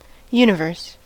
universe: Wikimedia Commons US English Pronunciations
En-us-universe.WAV